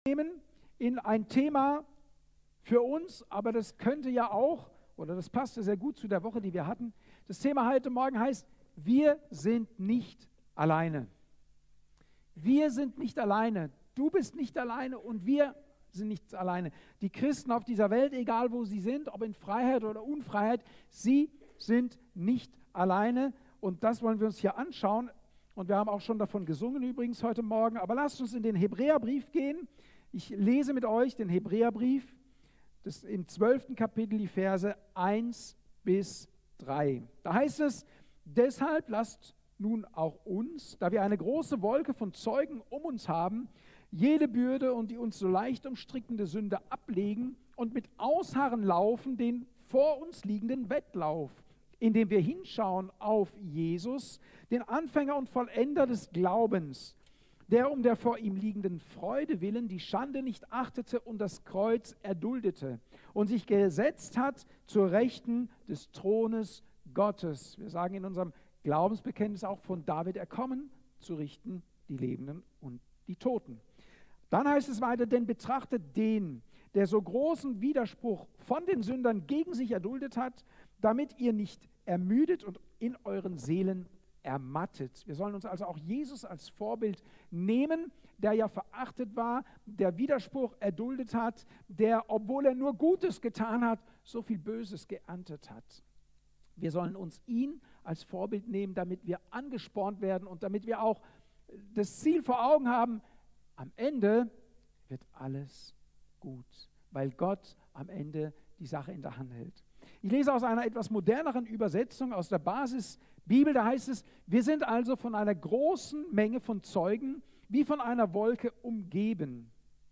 Thema: Wir sind nicht allein! Datum: 12.11.2023 Ort: Gospelhouse Kehl